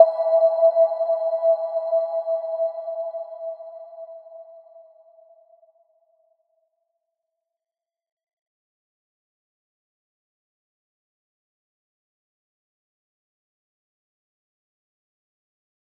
Little-Pluck-E5-f.wav